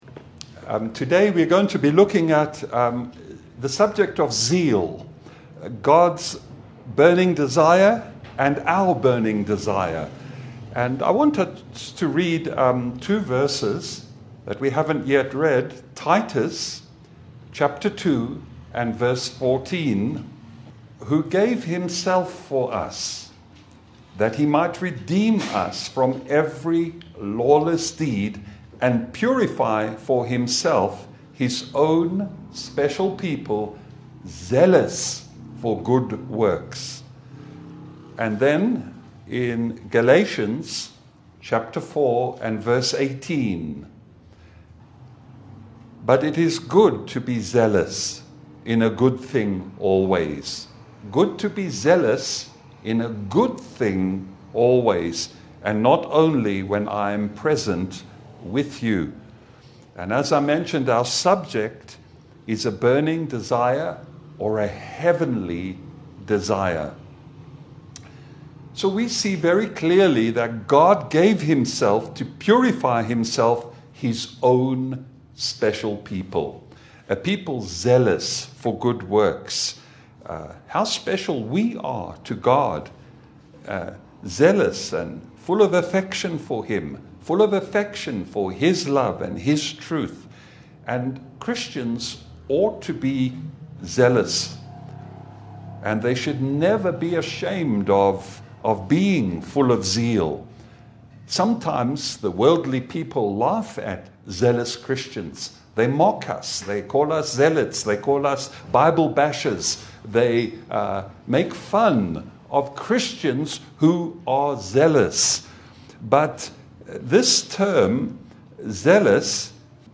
Service Type: Sunday Bible fellowship